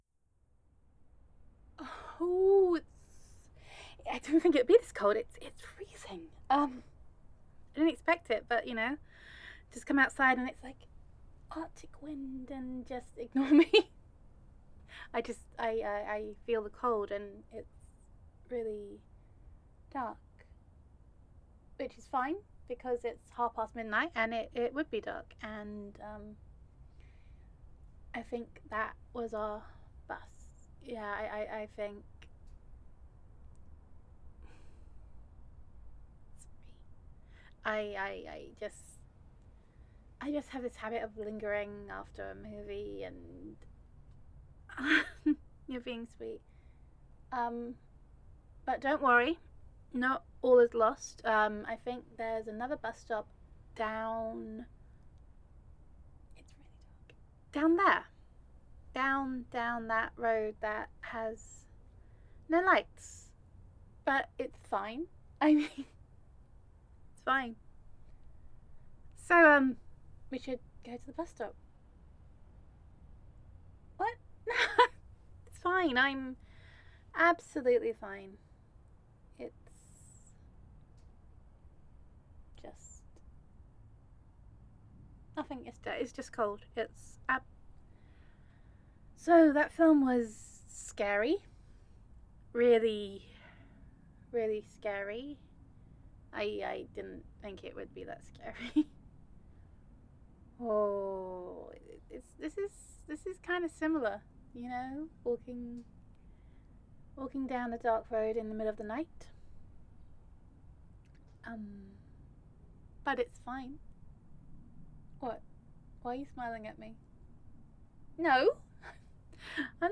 [F4A] Scared of the Dark [Would You Hold My Hand][Horror Movie Cliches][First Kiss][A Little Bit Ridiculous][Nervous Girlfriend][Things That Go Bump in the Night][Gender Neutral][Nervous Girlfriend Roleplay]